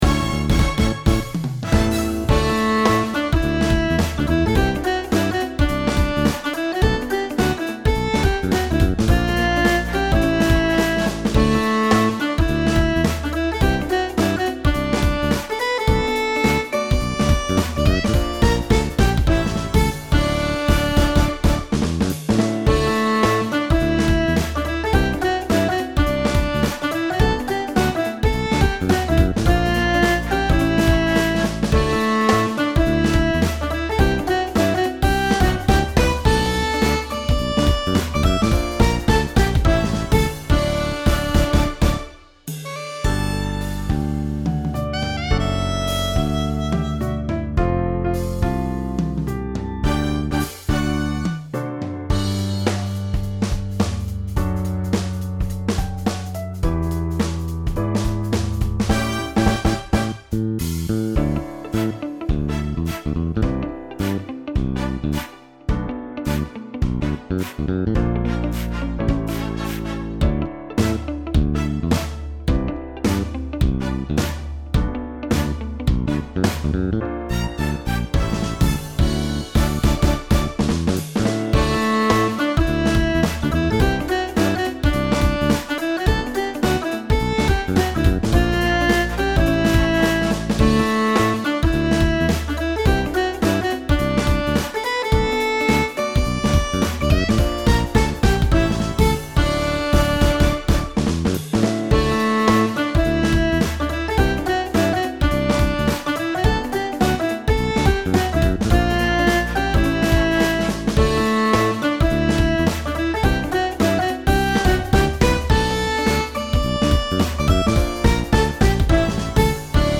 ポップでウキウキ感のある曲です。